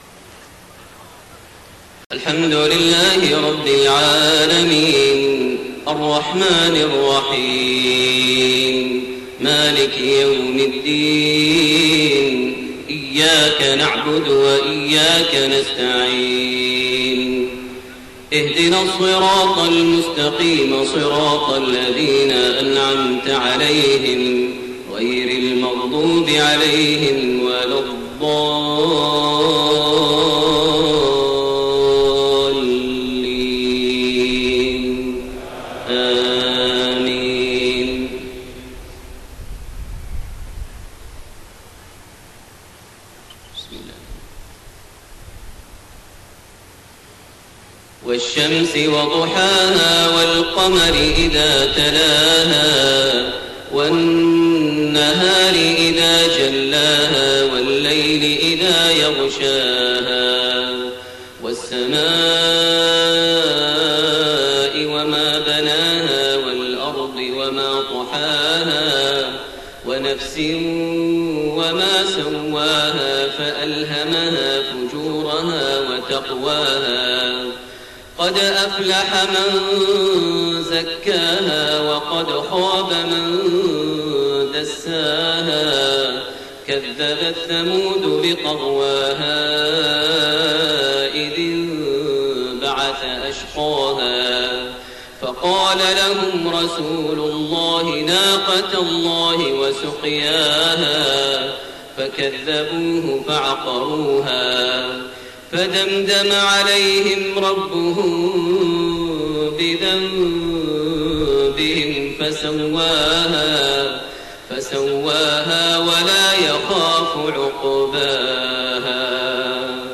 Maghrib prayer from Surat Ash-Shams and Al-Qaari'a > 1431 H > Prayers - Maher Almuaiqly Recitations